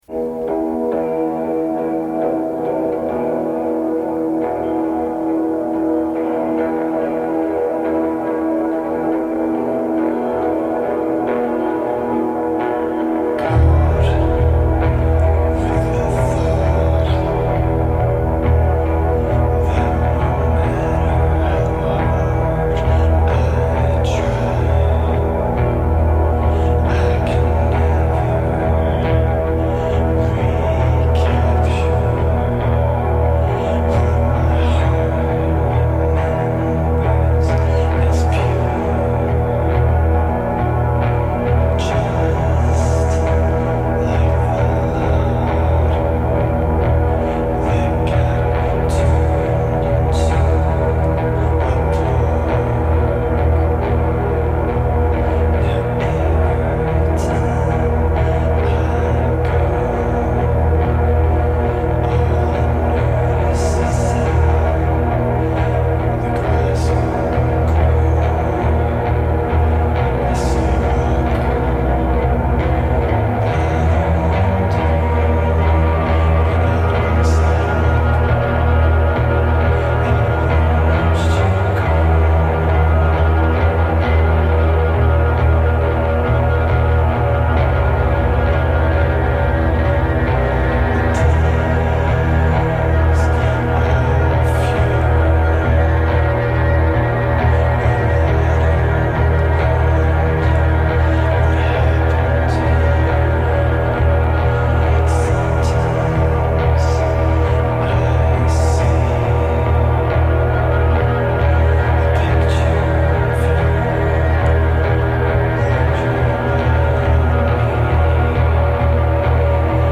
recorded at Maida Vale
Heading into Postrock territory
American post-rock group
bassist
guitarist and vocalist
keyboardist